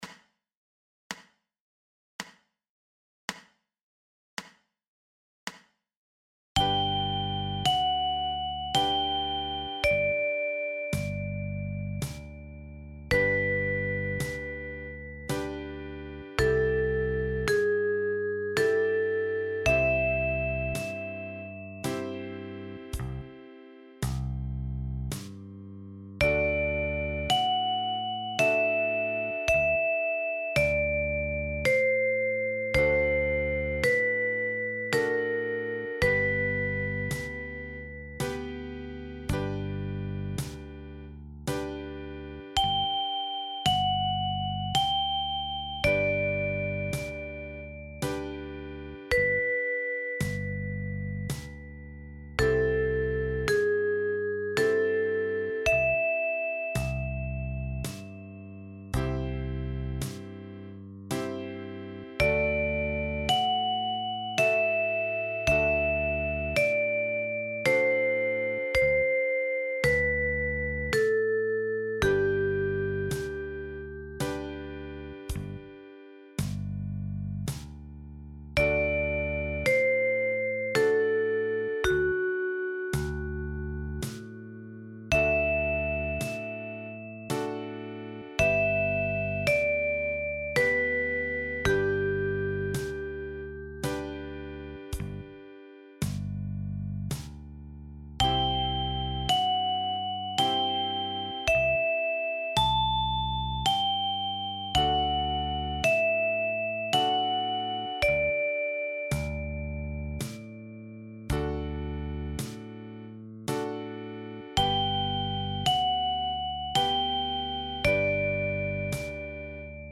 Folk & Gospel Songs für die Mandoline – mit Sounds